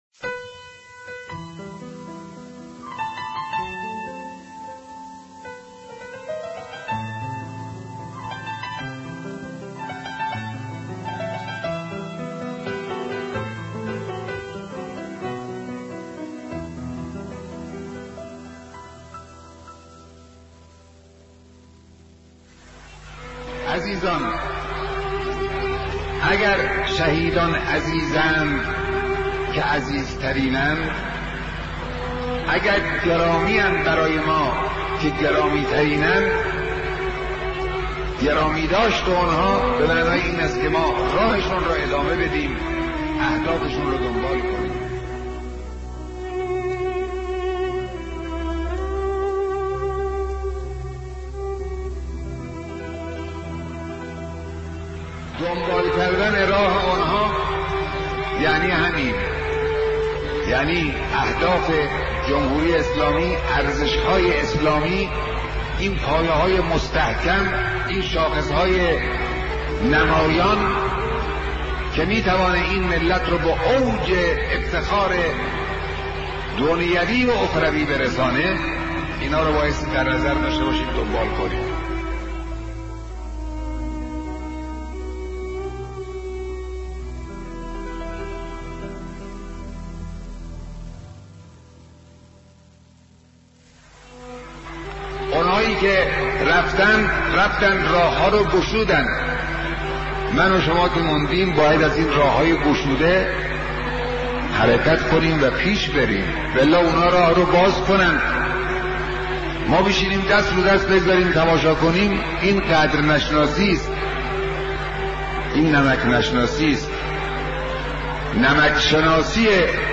گلف چند رسانه‌ای صوت بیانات بزرگان راه شهدا راه شهدا مرورگر شما از Player پشتیبانی نمی‌کند.